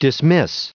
added pronounciation and merriam webster audio
1472_dismiss.ogg